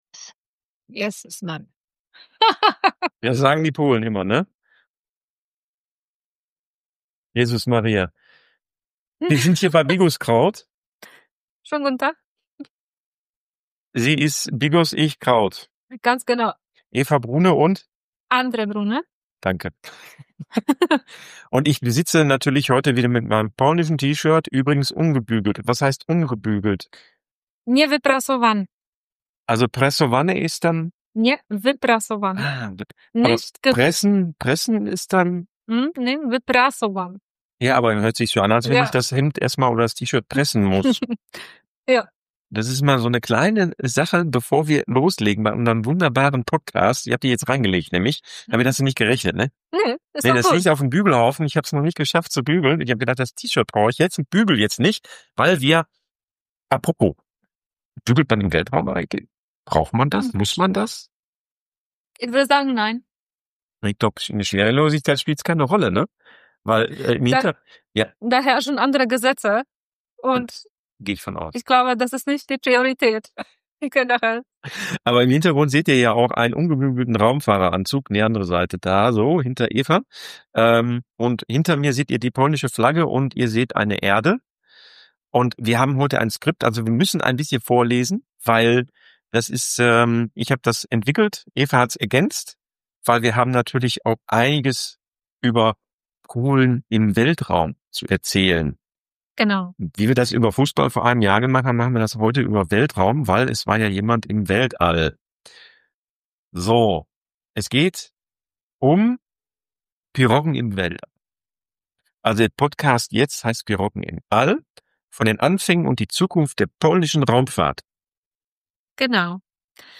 Die Tonqualität bitten wir zu entschuldigen. Die Lüftung vom Laptop hat uns die Aufnahme mit dem davorstehenden Mikrofon ein wenig versaut.
Das KI-Programm Auphonic hat das Beste herausgeholt.